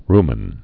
(rmən)